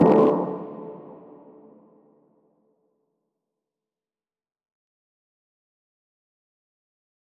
MDMV3 - Hit 14.wav